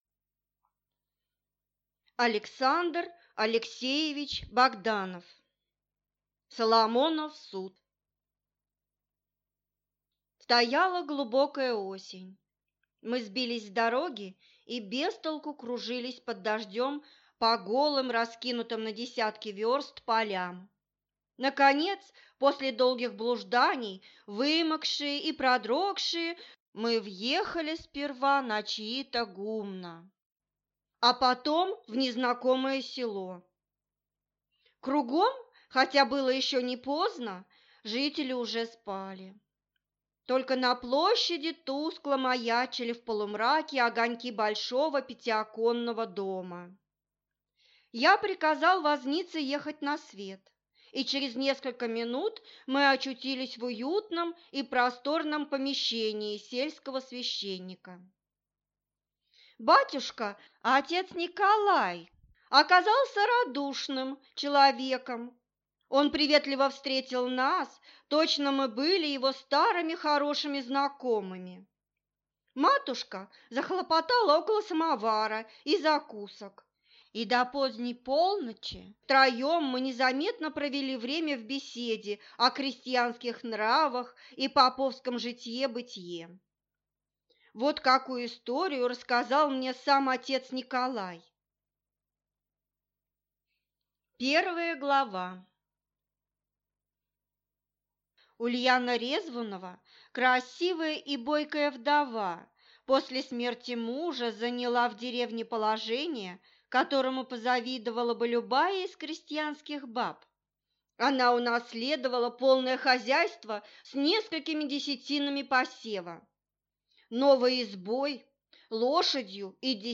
Аудиокнига Соломонов суд | Библиотека аудиокниг